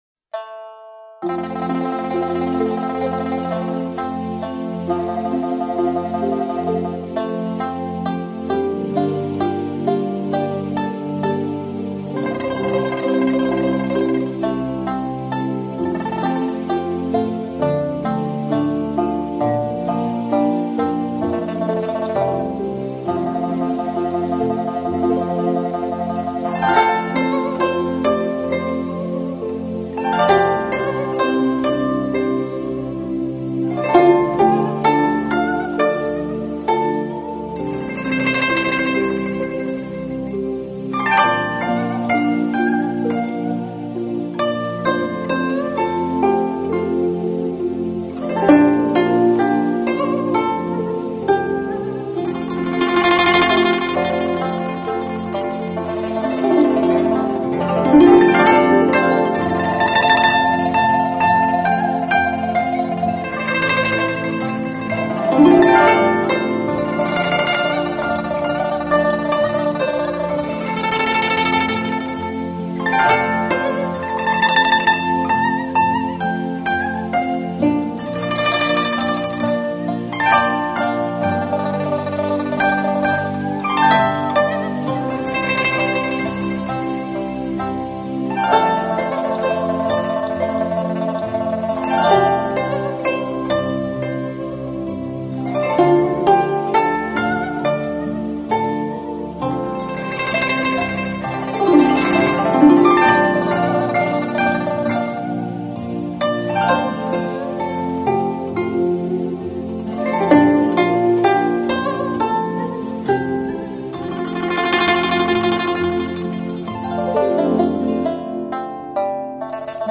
古筝高雅、古朴，音色优美，既有大气磅礴也有小桥流水，透着一股超凡脱俗的古典美。
流行金曲采用古筝特有的音色来表现，其韵味更是妙不可言。
古筝悦耳动听，雅俗共赏，在清脆悠扬的古筝声中，它将放飞你的思绪，带你去寻找那一方宁静！